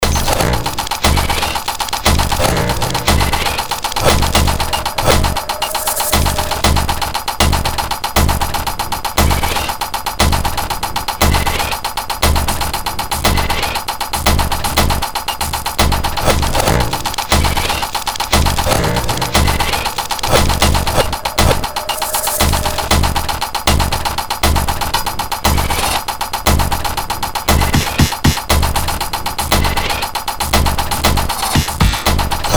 Trying come up with the most twisted, baleful sounding percussion possible without trailing off too far from the IDM genre.